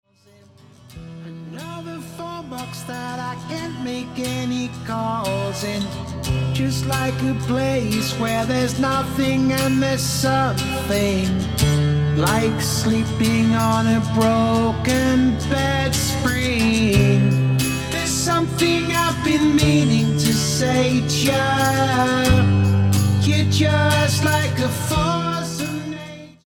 Semi Acoustic